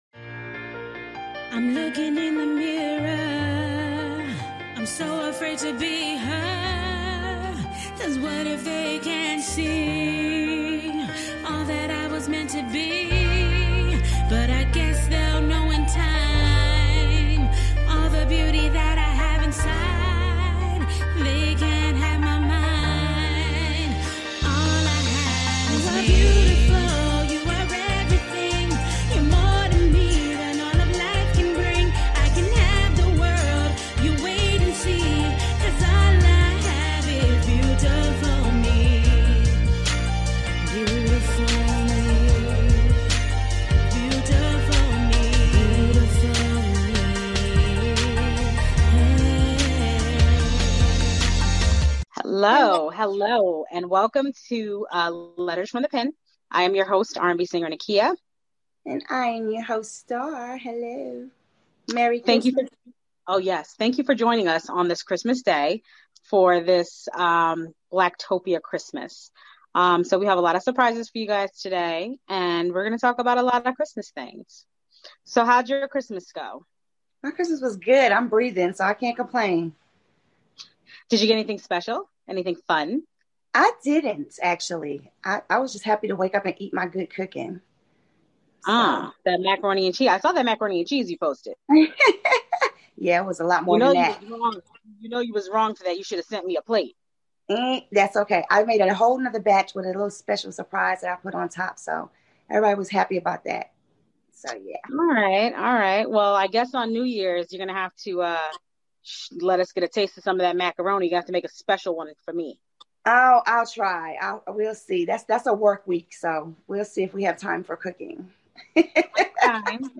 Letterz from the pen is a variety show touching base on new up and coming arts, entertainment, music, fashion, give relationship advice and analyze your letterz from the pen.